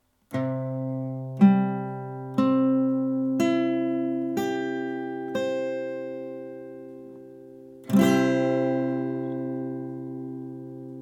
C-Dur (Barré, E-Saite)
C-Dur-Akkord, Barre, E-Saite, Gitarre
C-Dur-Barre-E.mp3